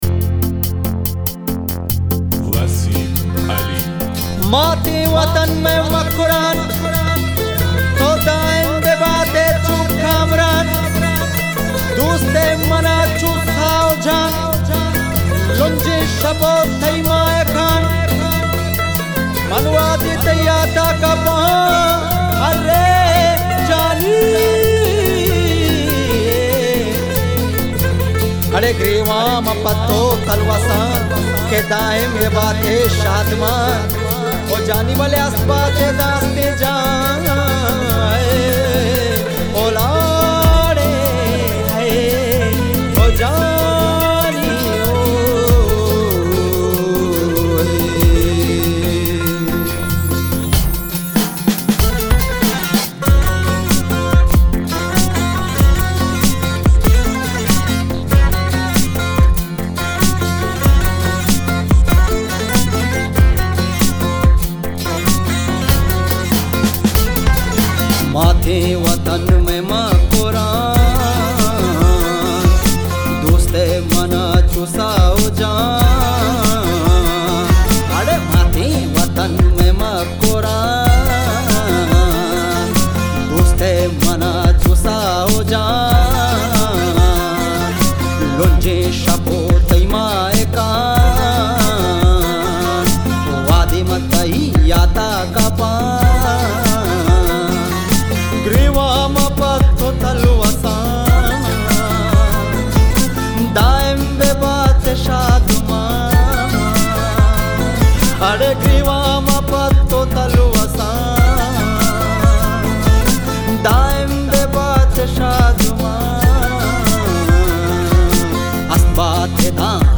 اهنگ بلوچی